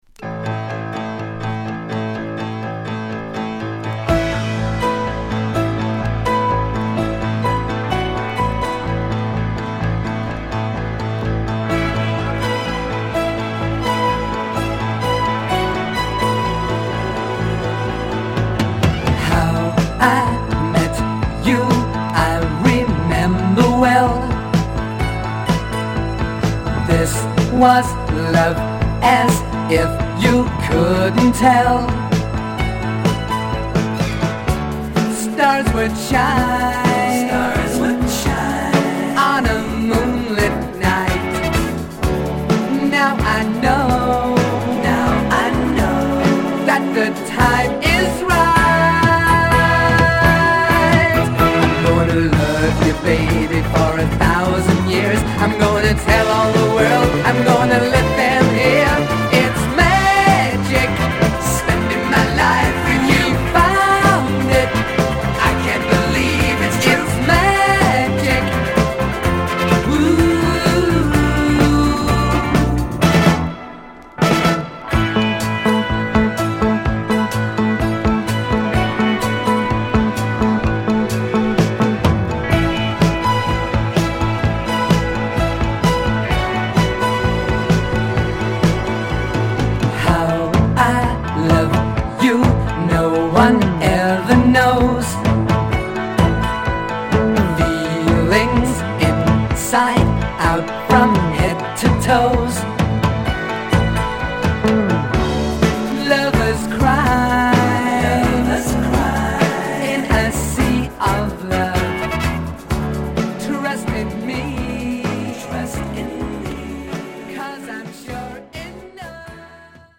良質シティポップが満載♪